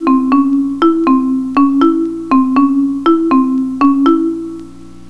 In the last example we combined the two mechanisms of choosing random pitches as well as durations.